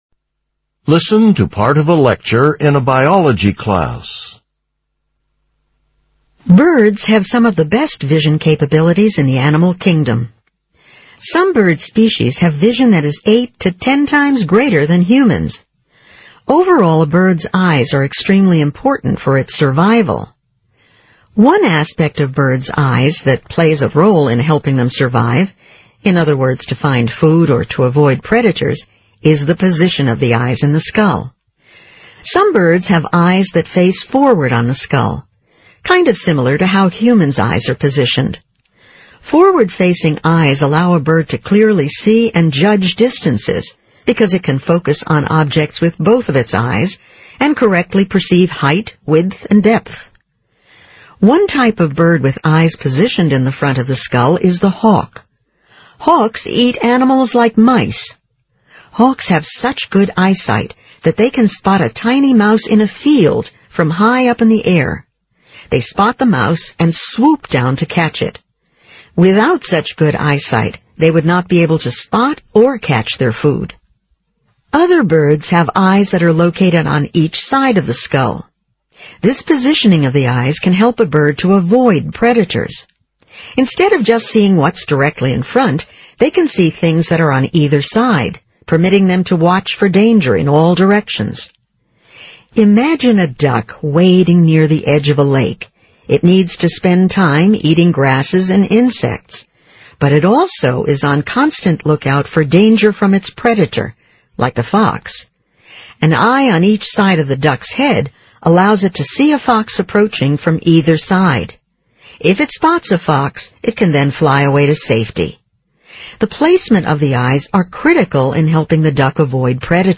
Using the examples in the lecture, explain how the position of birds’ eyes is critical to their survival.